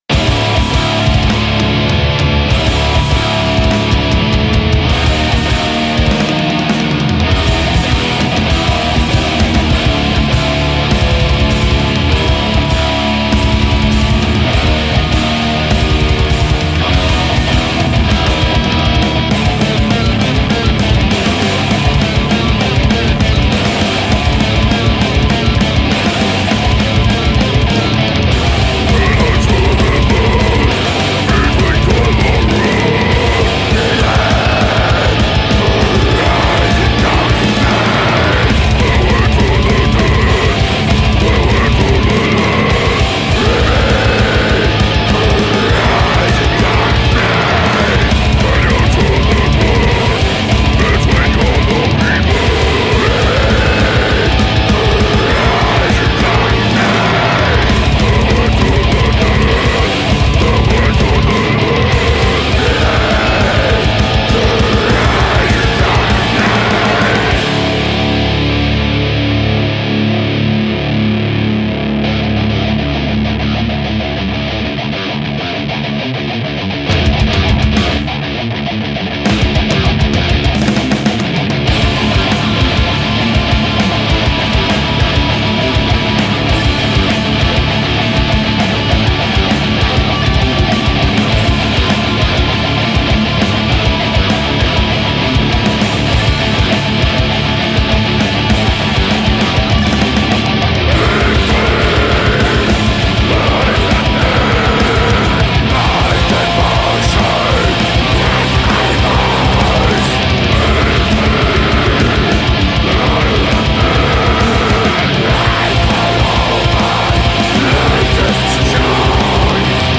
death metal-atmospherique France